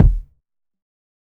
HFMKick2.wav